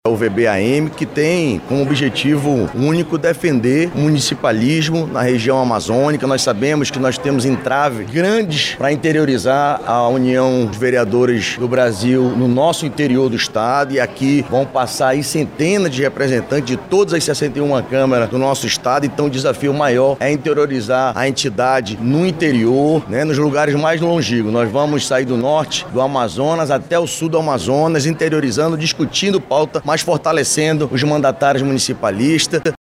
A posse da diretoria da União dos Vereadores do Brasil – Seccional Amazonas ocorreu na manhã desta sexta-feira 19/09, durante uma cerimônia solene, na Câmara Municipal de Manaus – CMM.
O vereador Diego Afonso, do União Brasil, que tomou posse como presidente da UVB Amazonas, destacou os desafios que precisam ser enfrentados, no Estado.
SONORA-2-POSSE-DIRETORIA-UVB.mp3